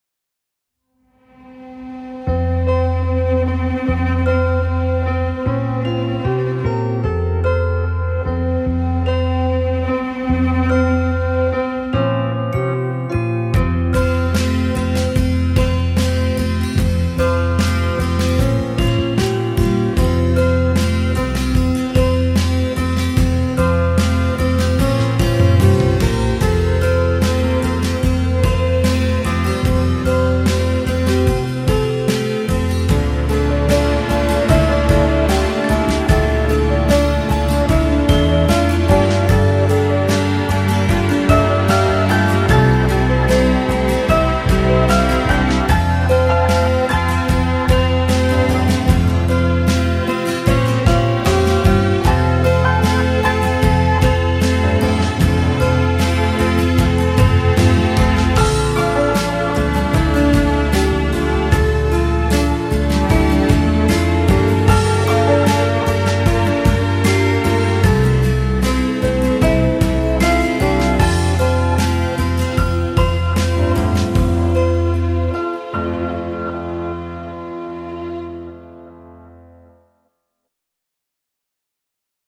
melodieux - rock - aerien - pop